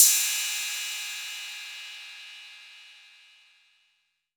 808CY_3_Orig.wav